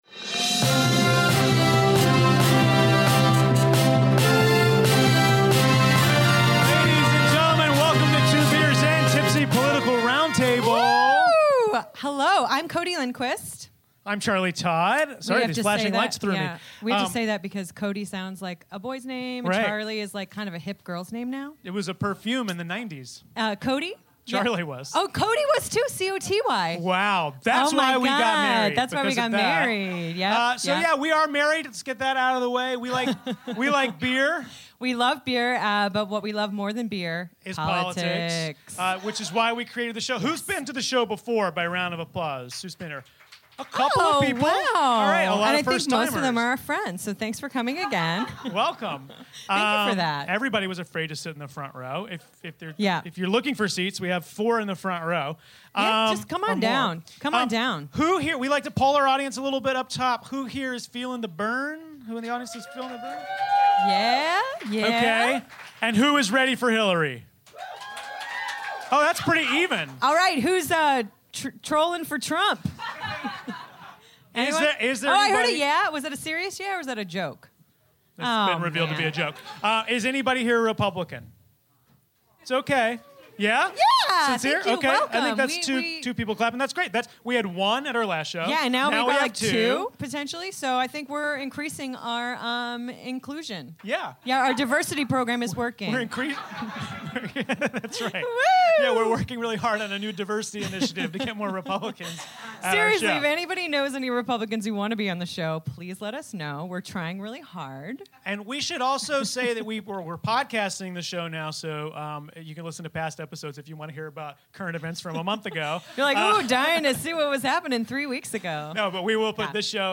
We chug two Montauk Session IPAs and talk Trump & the media, bathroom politics, and iPhone vs. FBI. Recorded live from the UCB Theatre East Village on March 29, 2016.